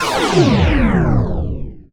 SCIFI_Down_06_mono.wav